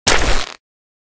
crack_wolf4.ogg